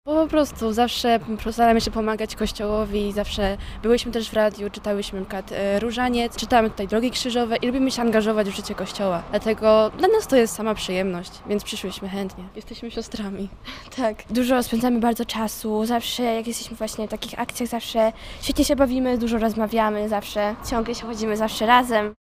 Okazją był 57 Światowy Dzień Środków Społecznego Przekazu.